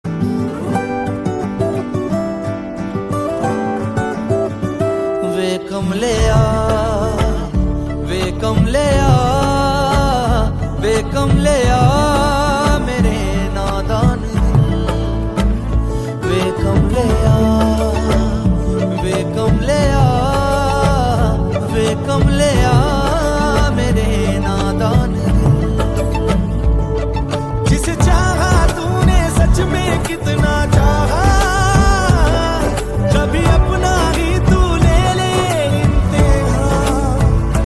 Categories Punjabi Ringtones